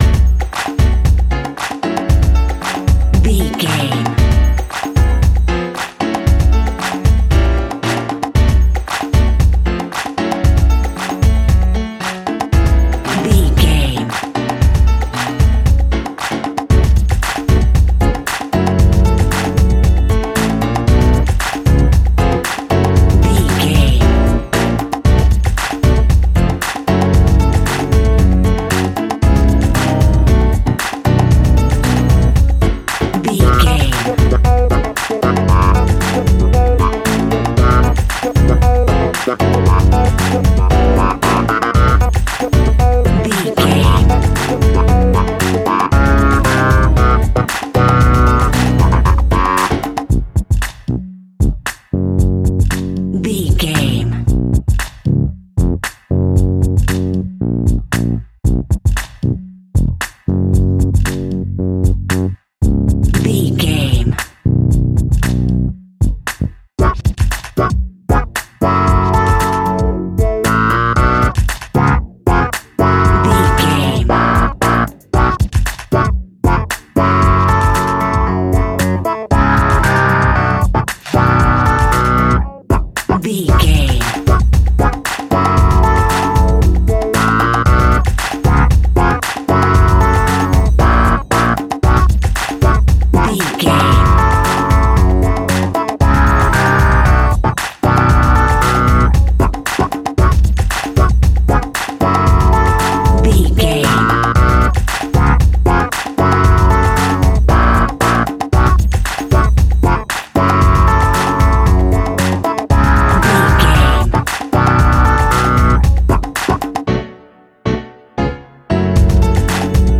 Aeolian/Minor
F#
drums
bass guitar
brass
saxophone
trumpet
fender rhodes
clavinet